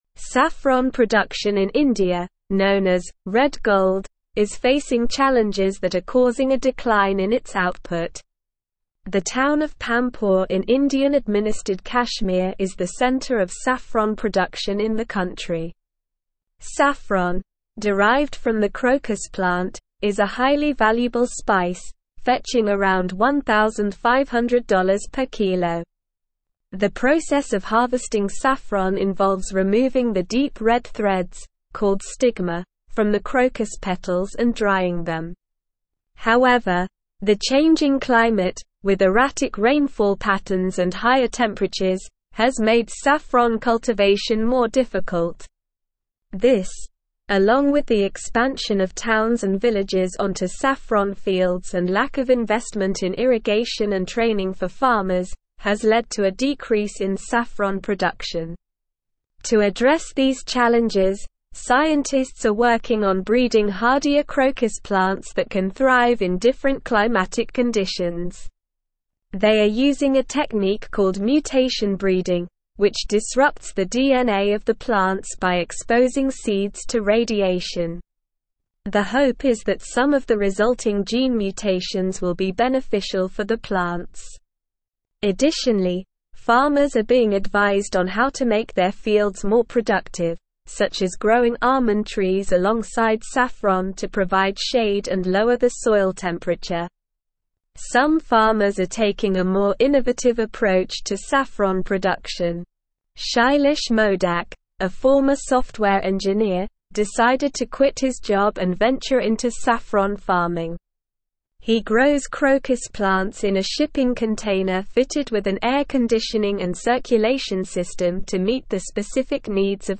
Slow
English-Newsroom-Advanced-SLOW-Reading-Saffron-production-in-India-challenges-and-solutions.mp3